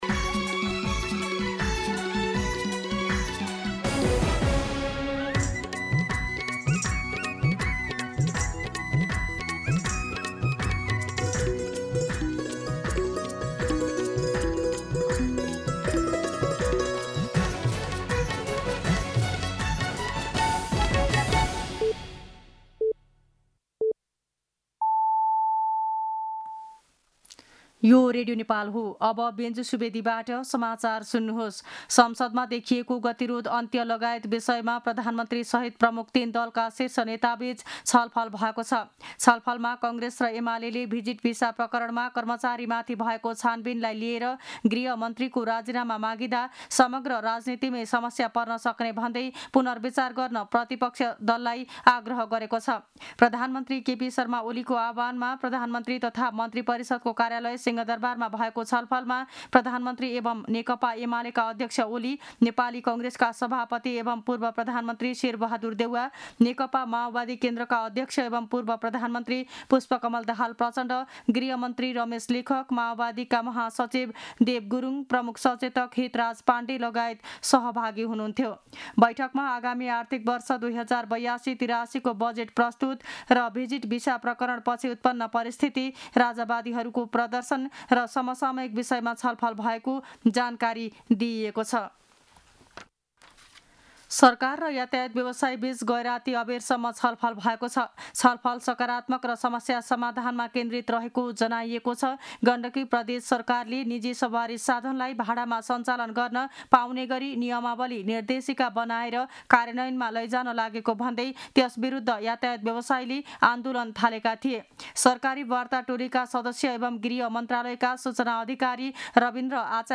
मध्यान्ह १२ बजेको नेपाली समाचार : २० जेठ , २०८२